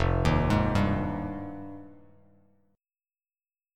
Fm9 Chord
Listen to Fm9 strummed